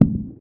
atrocinator_step.ogg